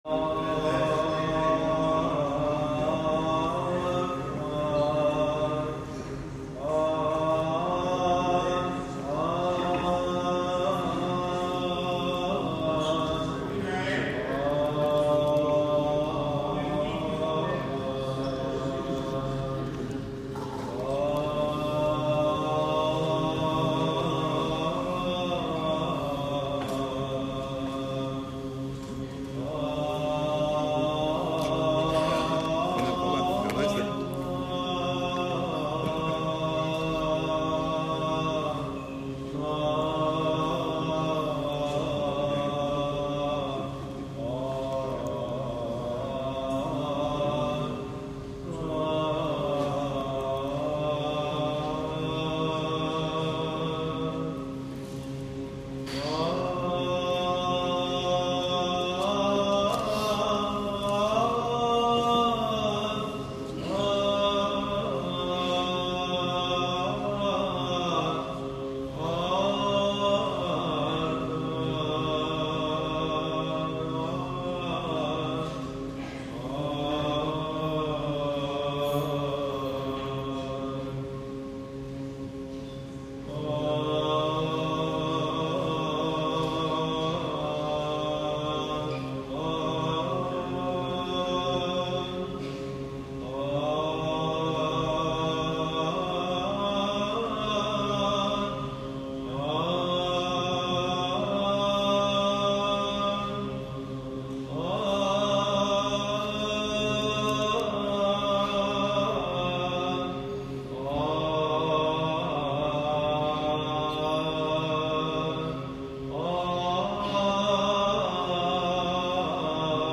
Στον Ιερό Μητροπολιτικό Ναό του Αγίου Γεωργίου στη Νεάπολη τελέστηκε με λαμπρότητα και μεγαλοπρέπεια ανήμερα των Θεοφανείων, Δευτέρα 6 Ιανουαρίου 2025, Αρχιερατική Θεία Λειτουργία και η Ακολουθία του Αγιασμού προεστώτος του Σεβασμιωτάτου Μητροπολίτη Νεαπόλεως και Σταυρουπόλεως κ. Βαρνάβα.